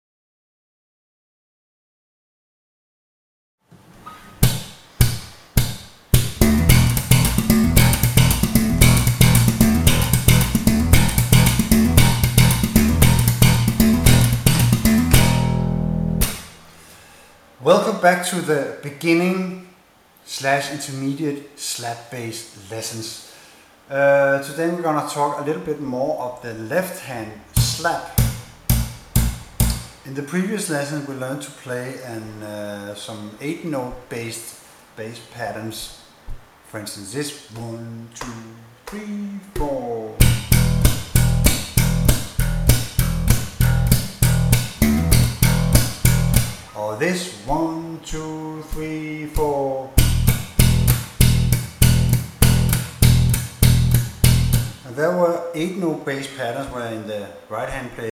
03 Slap Bass 101 For Novice Slappers
Using bass playing techniques made popular by Larry Graham, Louis Johnson, Victor Wooten, Bernard Edwards, Marcus Miller, Jaco Pastorius, Flea, Geddy Lee, among others.
03-Slap-bass-101Sample.mp3